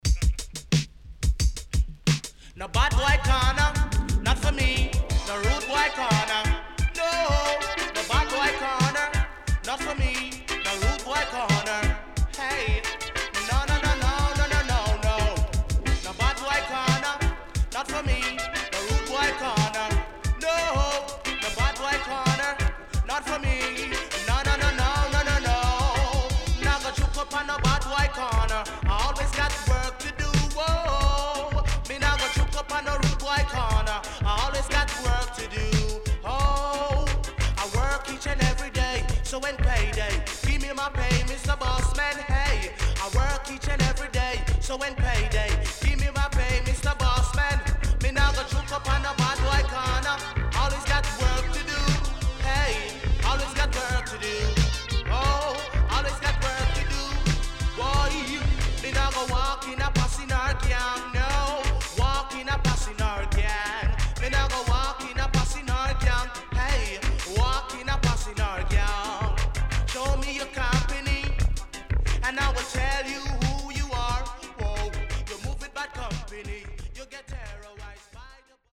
HOME > LP [DANCEHALL]  >  KILLER
SIDE A:少しチリノイズ入りますが良好です。
SIDE B:少しチリノイズ入りますが良好です。